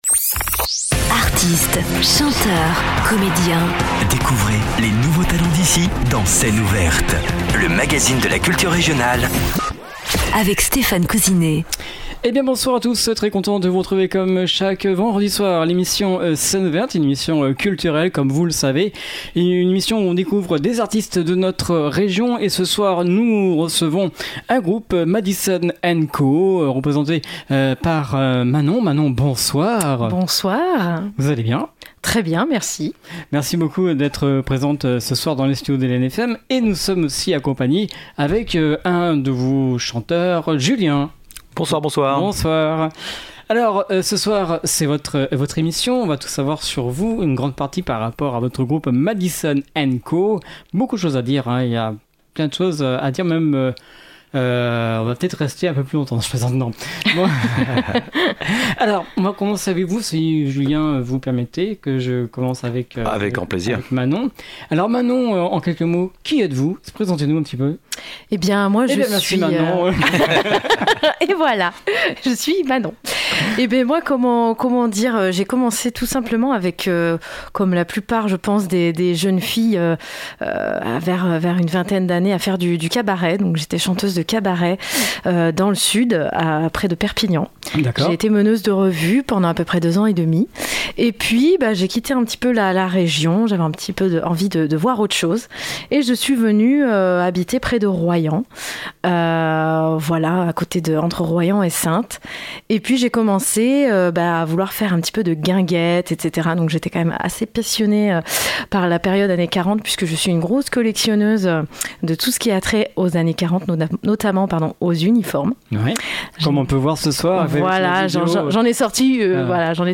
Allant de 2 à 9 artistes